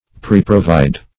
Search Result for " preprovide" : The Collaborative International Dictionary of English v.0.48: Preprovide \Pre`pro*vide"\, v. t. To provide beforehand.